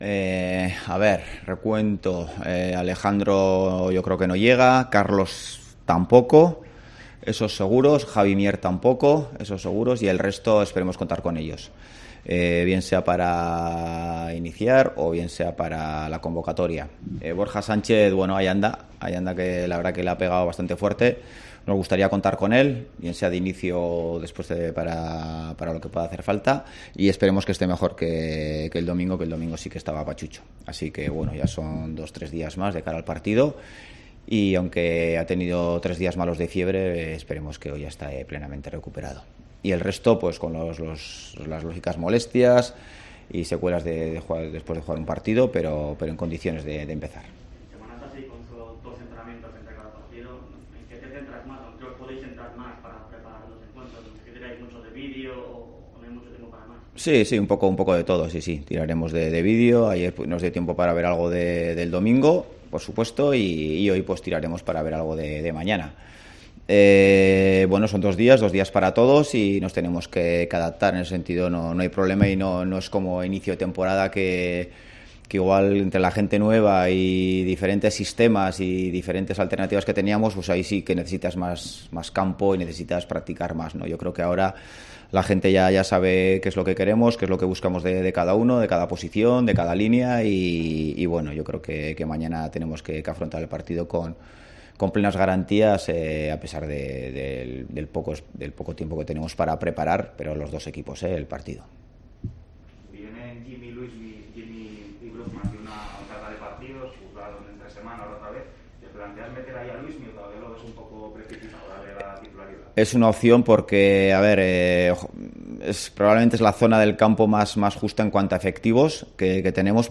Rueda de prenda Ziganda (previa Eibar-Oviedo)